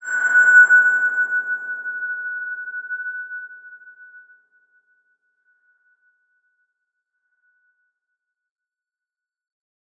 X_BasicBells-F#4-mf.wav